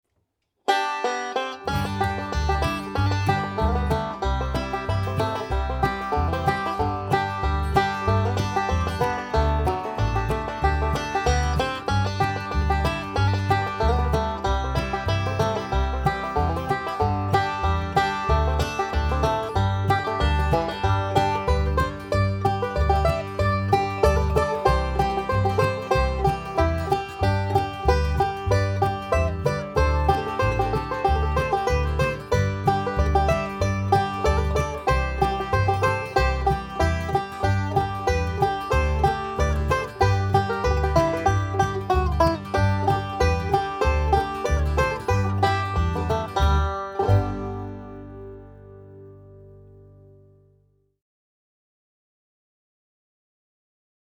DIGITAL SHEET MUSIC - 5-STRING BANJO SOLO
Three-finger "Scruggs" style
learning speed and performing speed